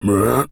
Male_Grunt_Hit_10.wav